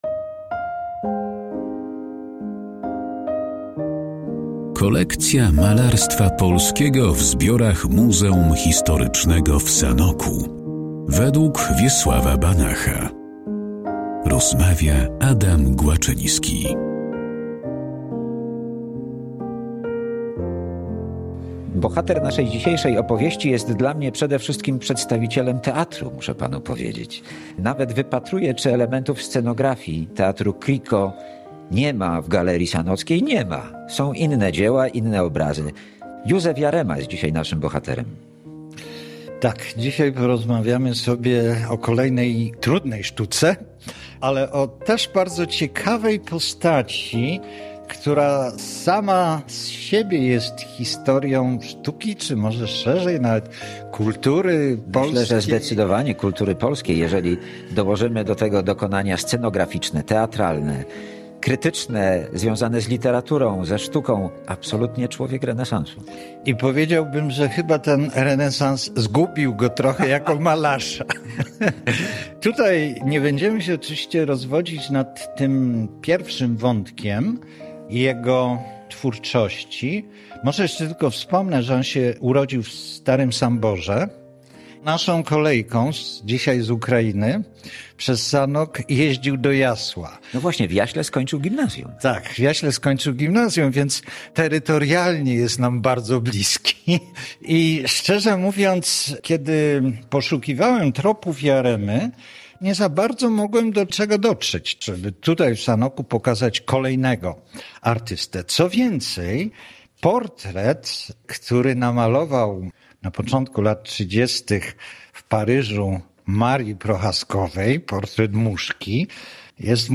historyk sztuki